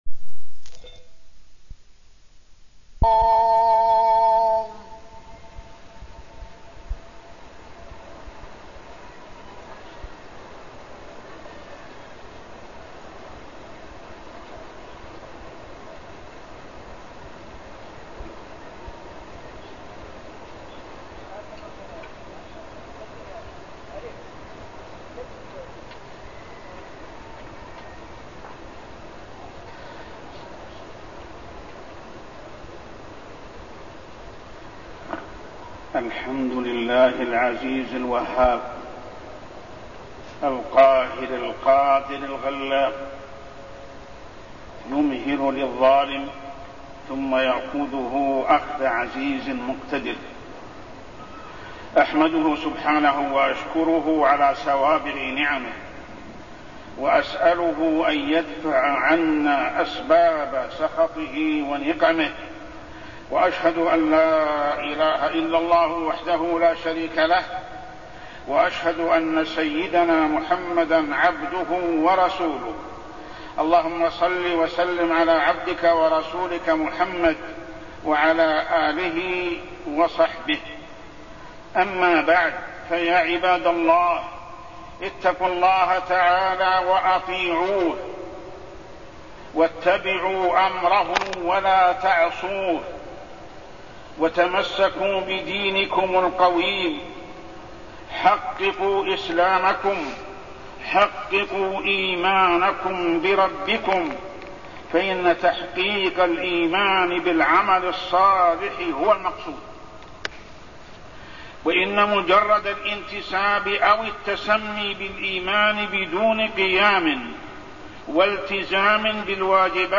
تاريخ النشر ٩ رجب ١٤٢١ هـ المكان: المسجد الحرام الشيخ: محمد بن عبد الله السبيل محمد بن عبد الله السبيل الحب في الله والبغض في الله